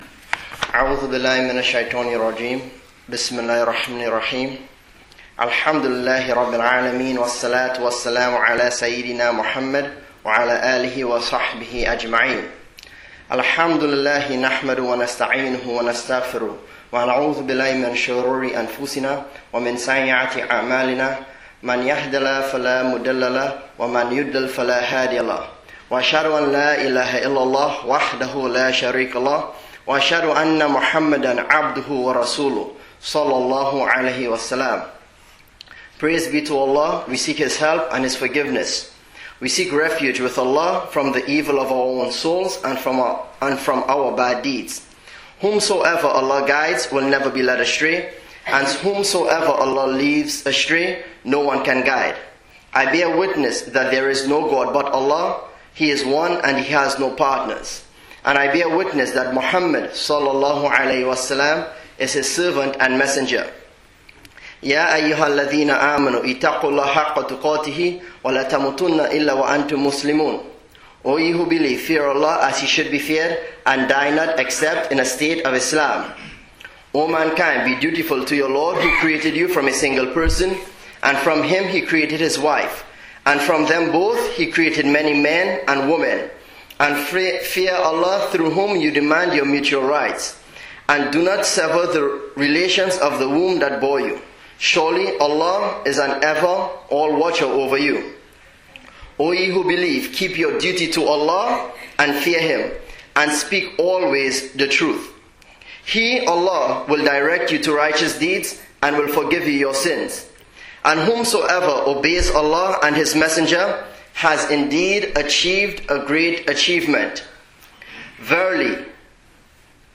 Jumu'ah Khutbah: Loyalty: Moving from Mundane Connections to Arcane Realities (Masjid Quba | 4/15/2011)